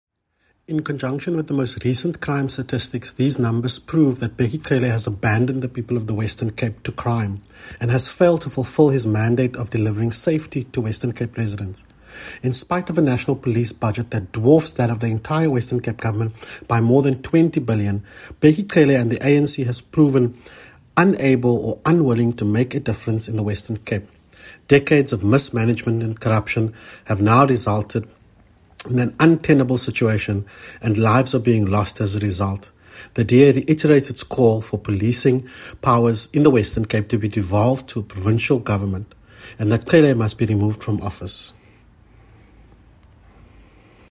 English audio by MPP Gillion Bosman attached.